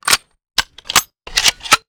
antitank_reload_02.wav